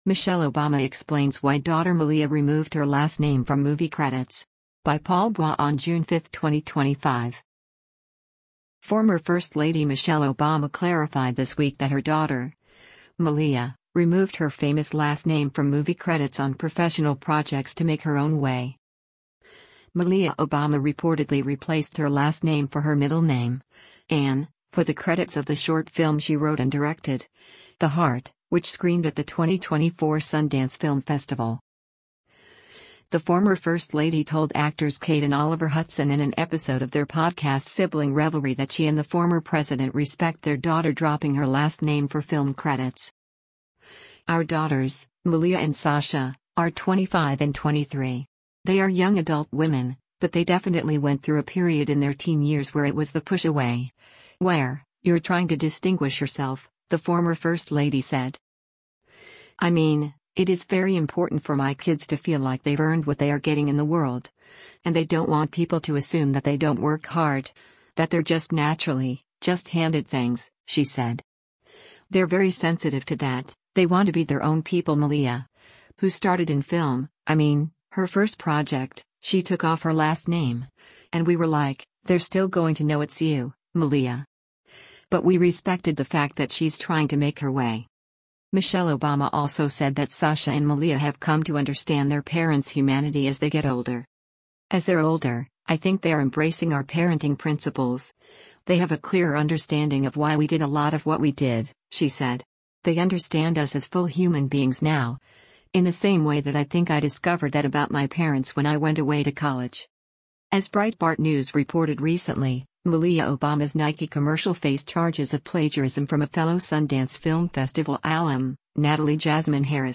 The former first lady told actors Kate and Oliver Hudson in an episode of their podcast Sibling Revelry that she and the former president respect their daughter dropping her last name for film credits.